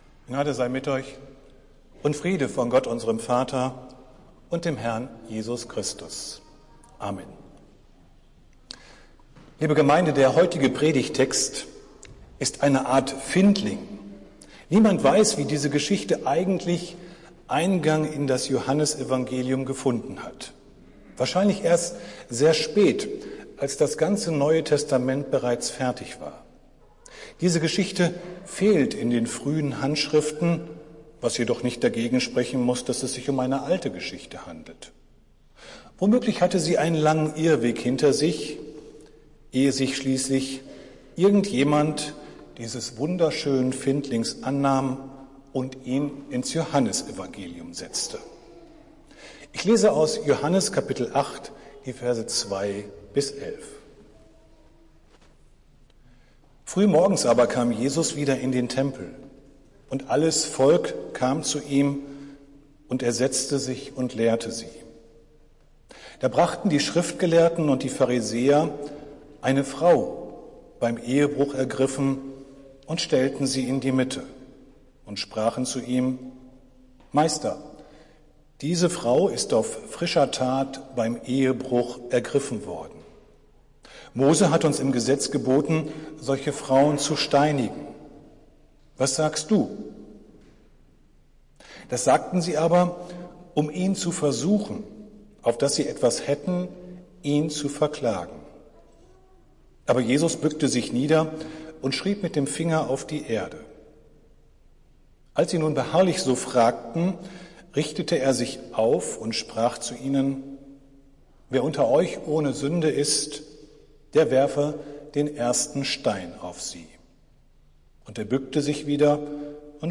Predigt des Gottesdienstes aus der Zionskirche am Sonntag, den 10.07.2022